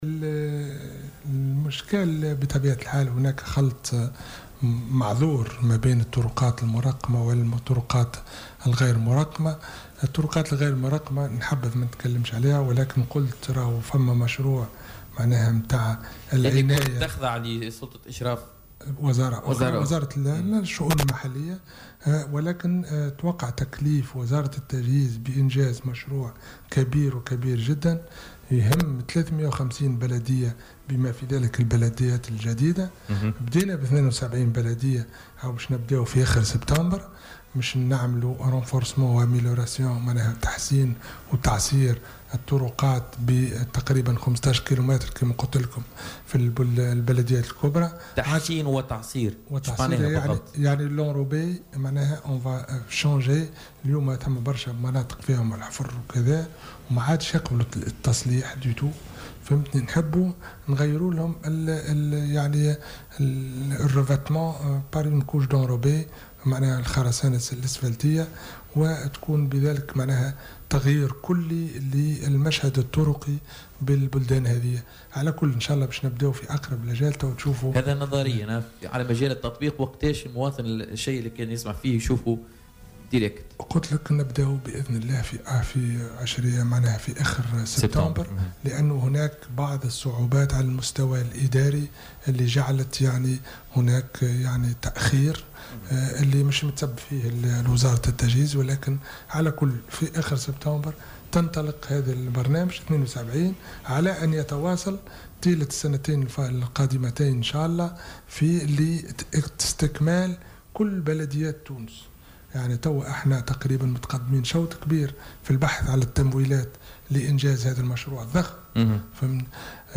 وأوضح في مداخلة له اليوم في برنامج "بوليتيكا" أن المشروع يهم 350 بلدية، مشيرا إلى أن الأشغال ستشمل في مرحلة أولى 72 بلدية وذلك قبل موفى شهر سبتمبر الحالي.